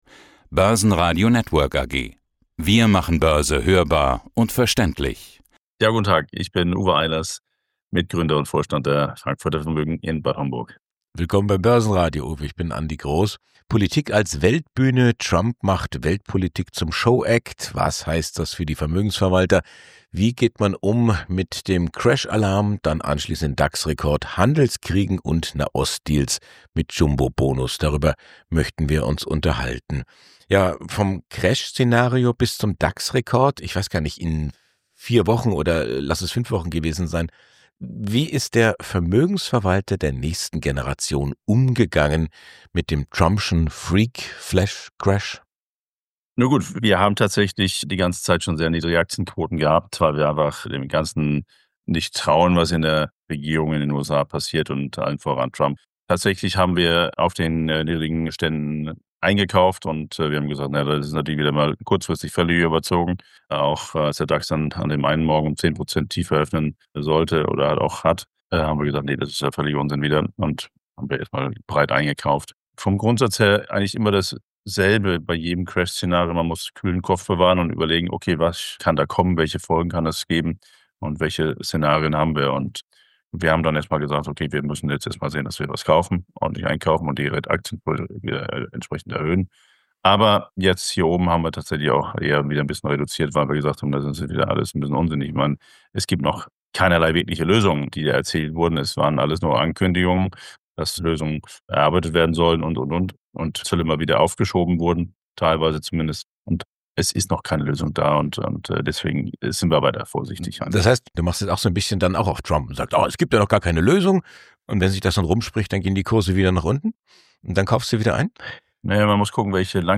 Radio report: ‘Have bought broadly – I don’t trust the US dollar!’